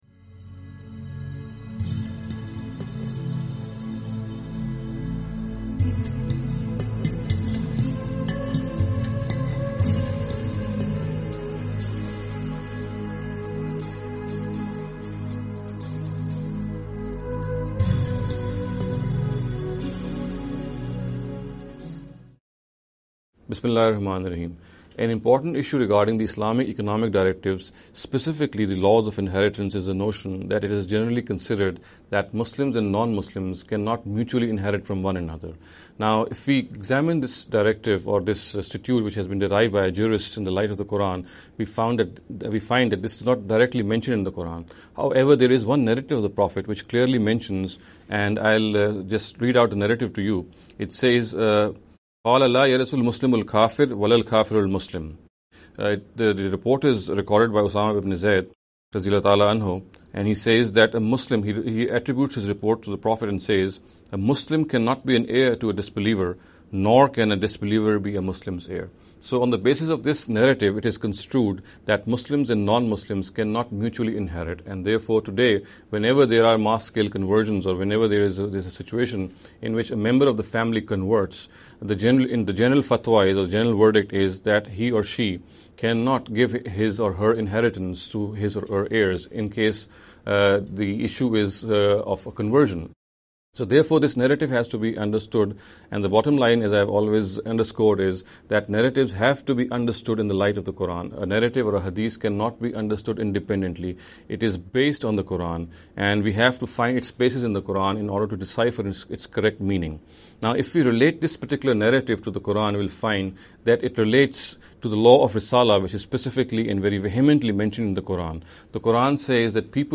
This lecture series will deal with some misconception regarding the Economic Directives of Islam.